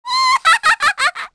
Hanus-Vox_Happy3_kr.wav